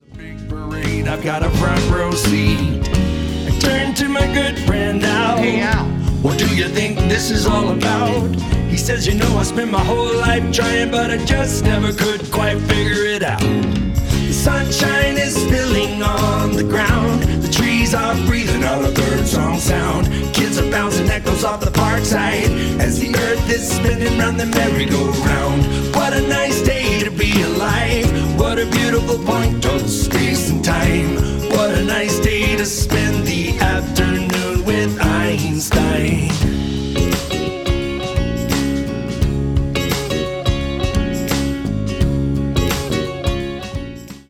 The lyrics to this song are a few years old. I finally decided to put them to music and started on a bluegrass tune.